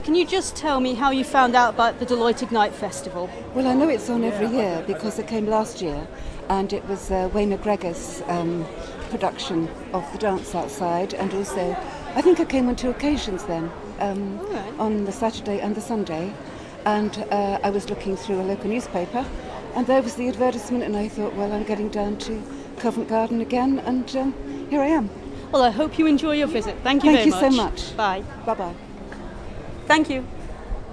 Deloitte Ignite - Interview 1